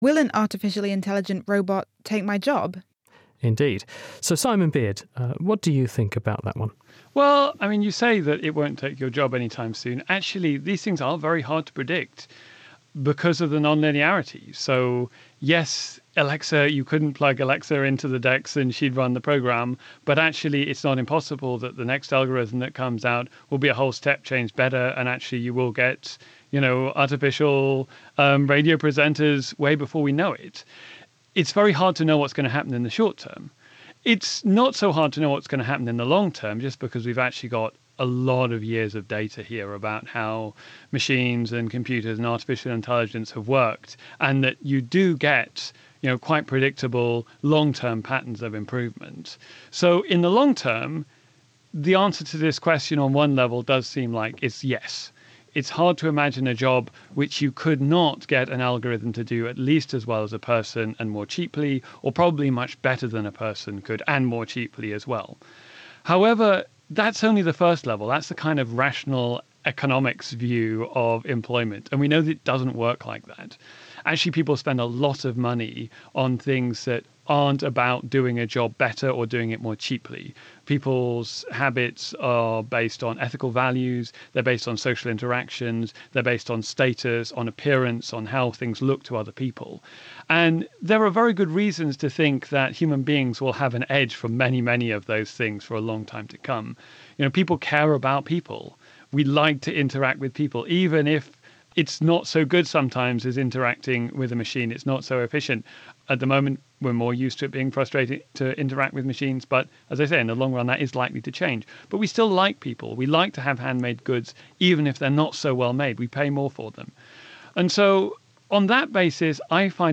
Interview with